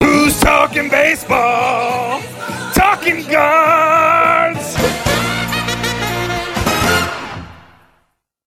talkin-baseball-talkin-guards-loud-1.mp3